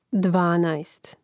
dvánaest dvanaest